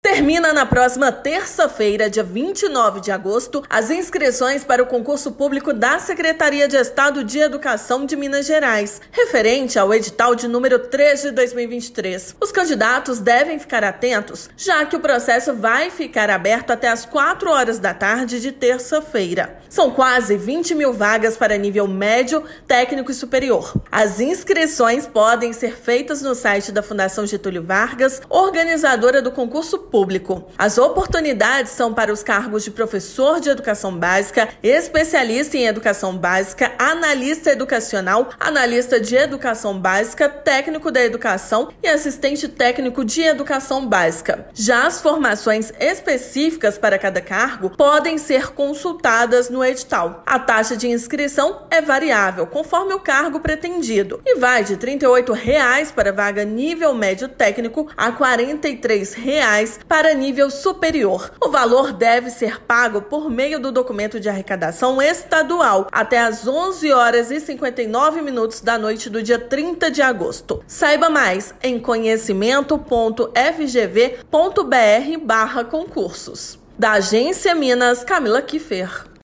Candidatos devem fazer inscrição até às 16h da próxima terça-feira (29/8). Ouça a matéria de rádio.